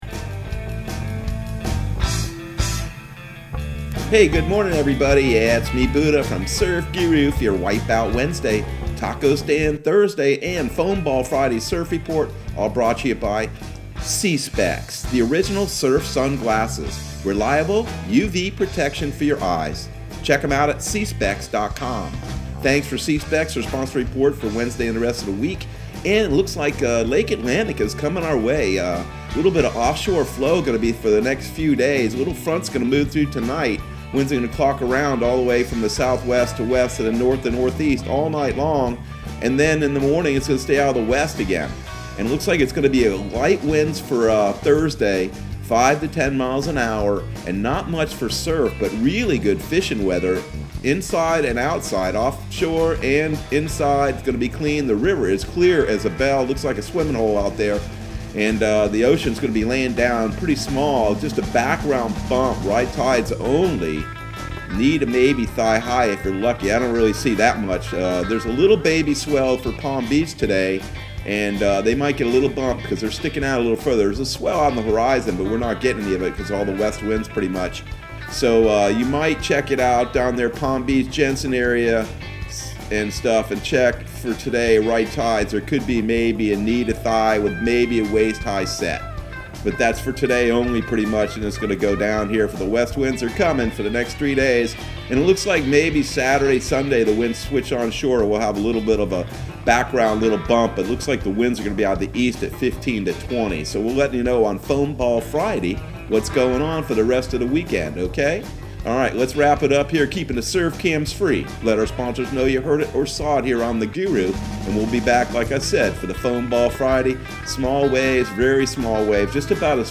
Surf Guru Surf Report and Forecast 01/20/2021 Audio surf report and surf forecast on January 20 for Central Florida and the Southeast.